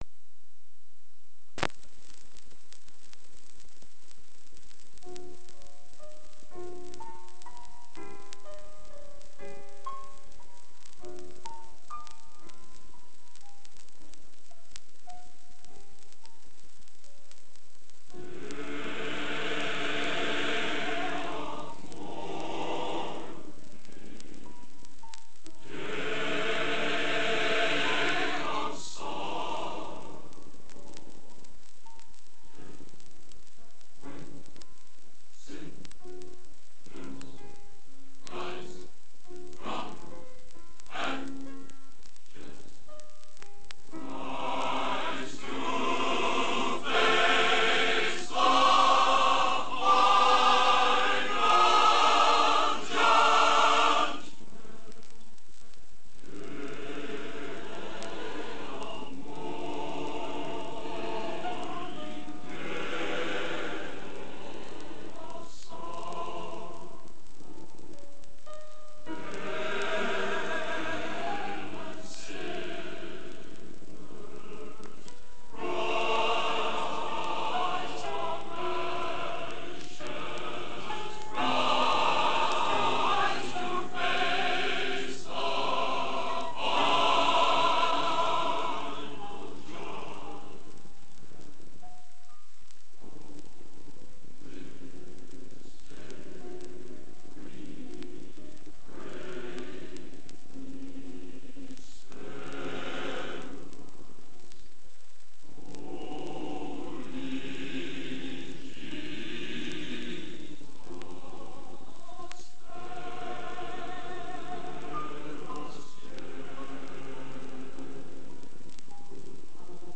Genre: Classical | Type: Studio Recording